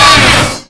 sawstrike1.wav